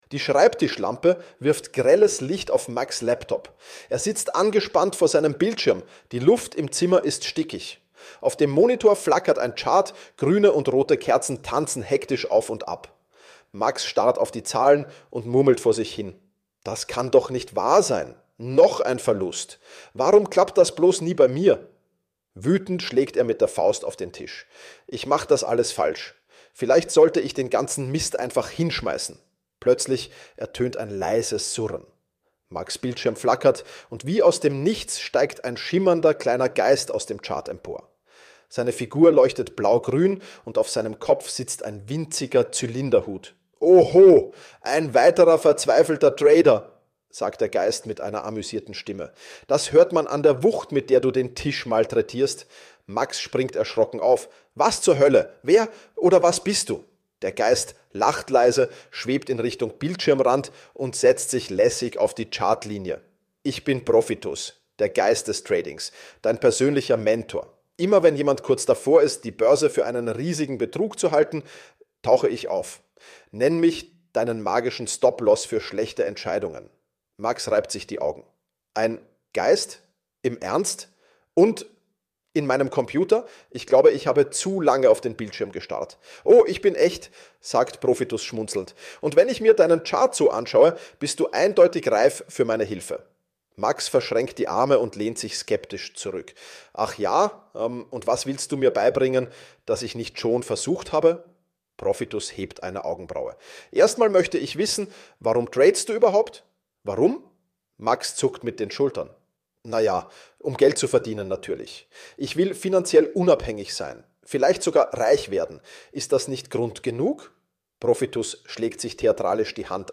Meine Stimme wurde dafür geklont.